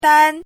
chinese-voice - 汉字语音库